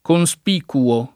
conspicuo [ kon S p & kuo ]